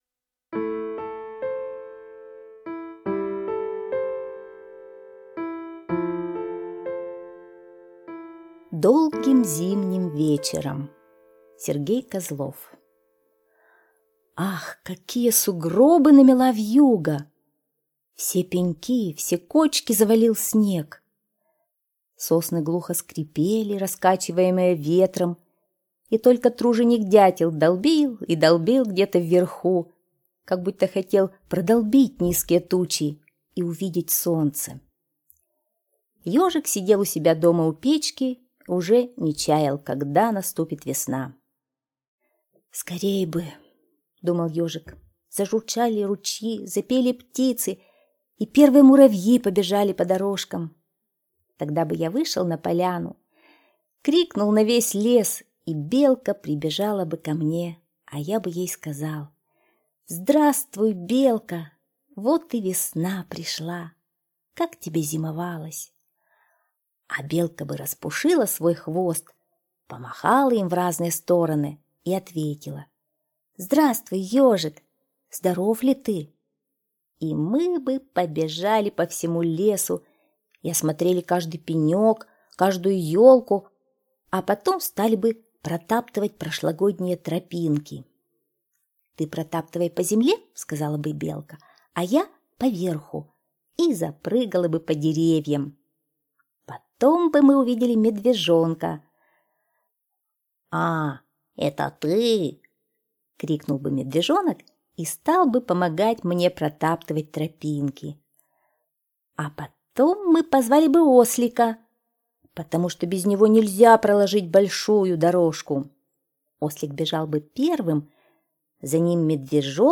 Слушайте Долгим зимним вечером - аудиосказка Козлова С.Г. Сказка про Ежика, который зимними вечерами мечтал о том, как весной он встретится с друзьями.